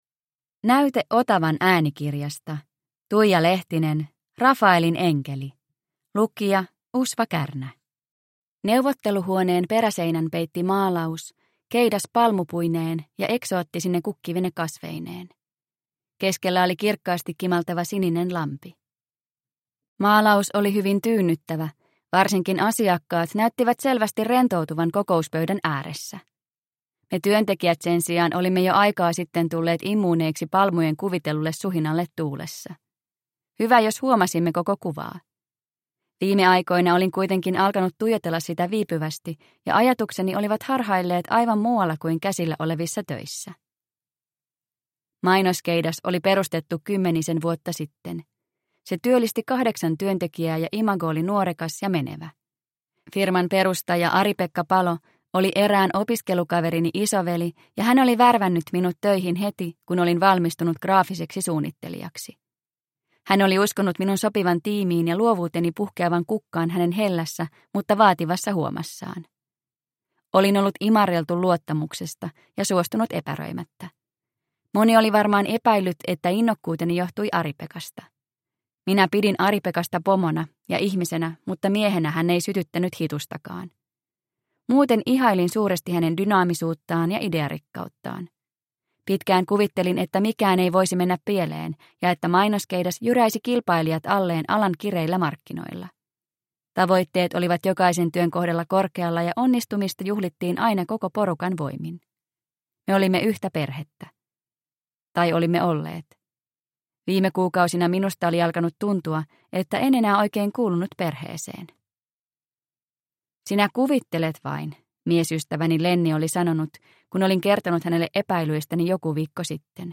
Rafaelin enkeli – Ljudbok – Laddas ner